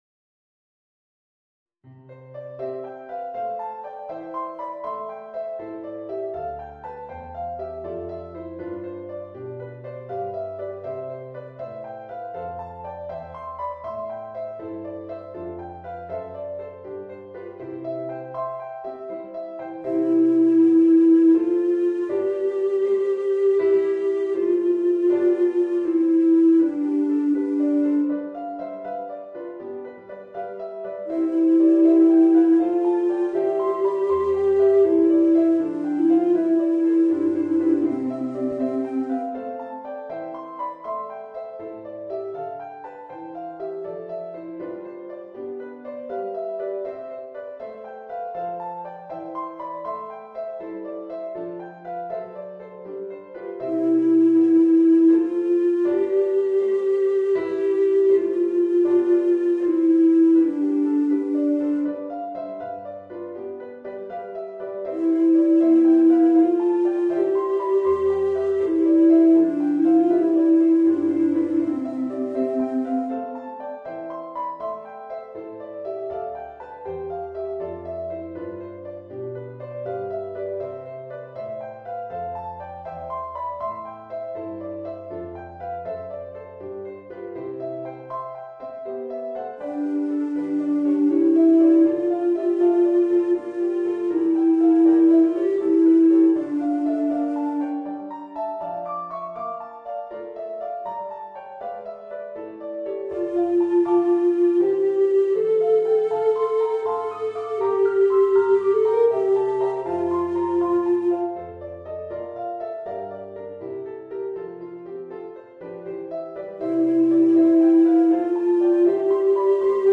Voicing: Bass Recorder and Piano